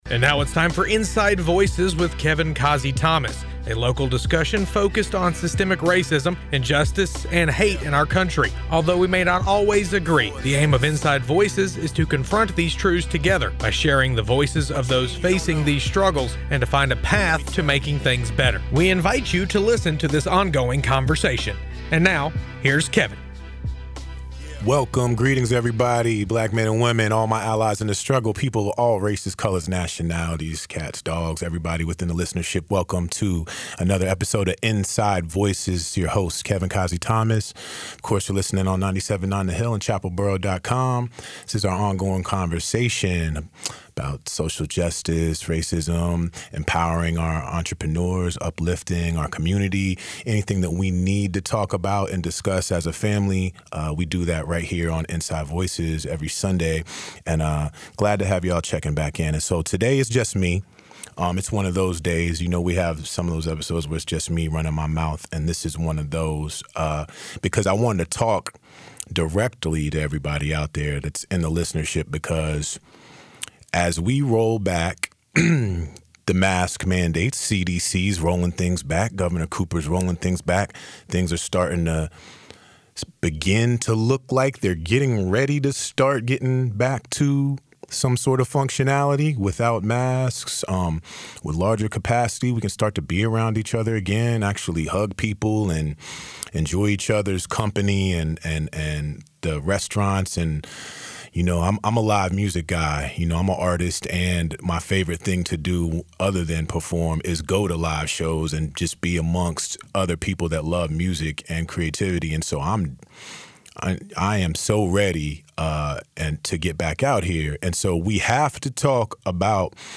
one-on-one studio session discussing the latest developments in vaccines and shifts in how the world works